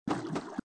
AV_footstep_runloop_water.ogg